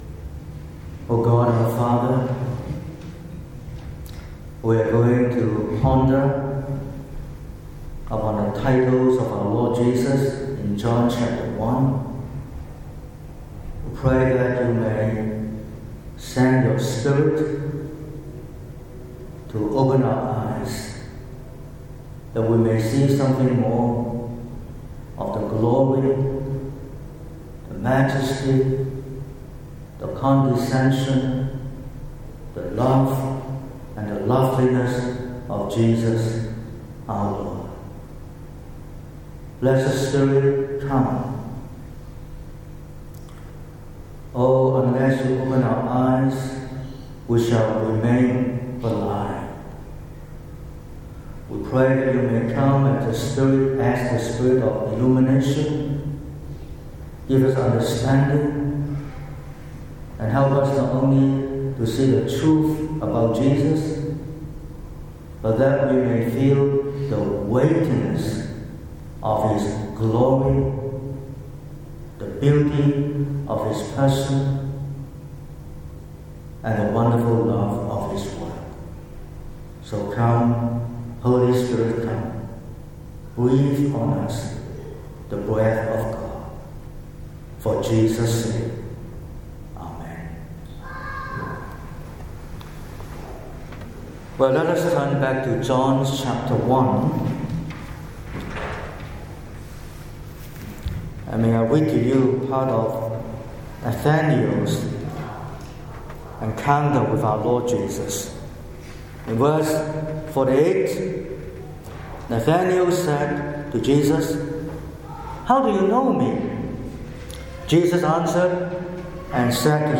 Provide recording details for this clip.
09/11/2025 – Evening Service: The seven titles of our Lord in John 1